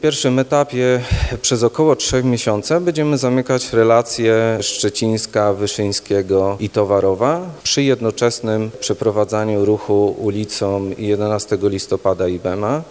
– mówił podczas sesji rady miejskiej